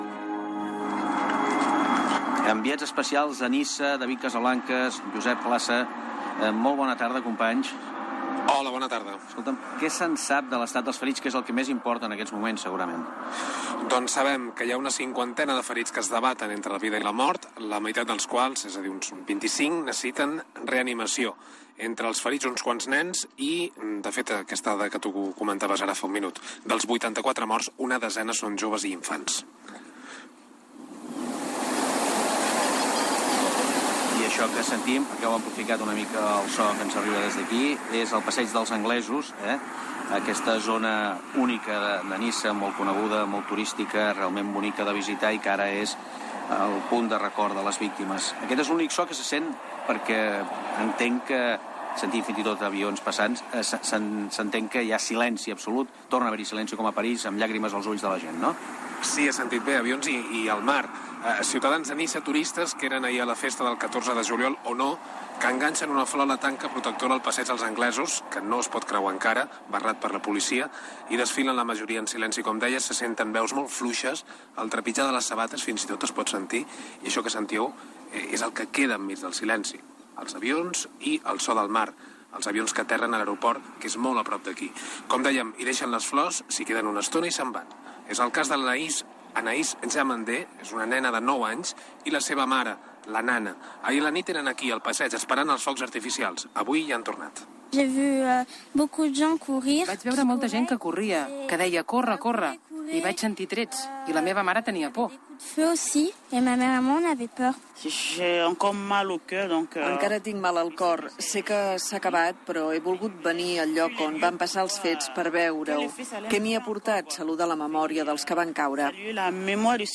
Connexió amb Niça, amb el passeig dels Anglesos, on, unes hores abans, un camió atropella indiscriminadament les persones que celebrevaben la festa nacional francesa.
Informatiu